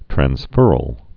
(trăns-fûrəl)